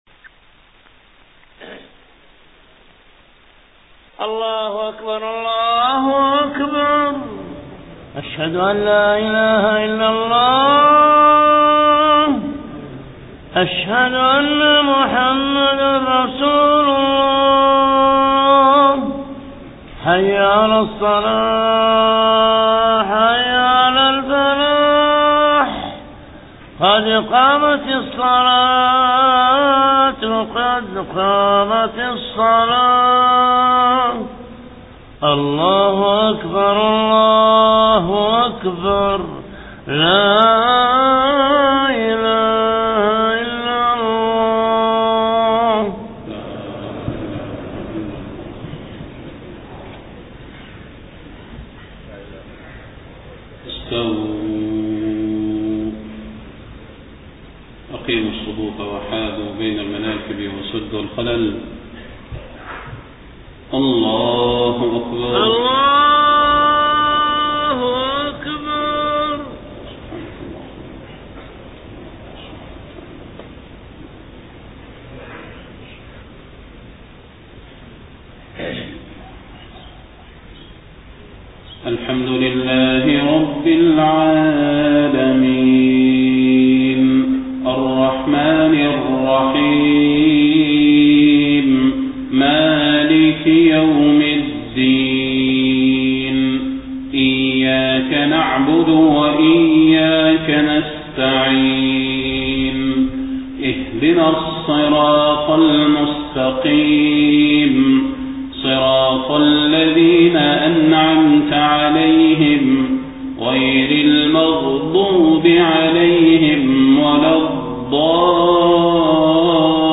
صلاة الفجر 1 ربيع الأول 1431هـ سورة الطور كاملة > 1431 🕌 > الفروض - تلاوات الحرمين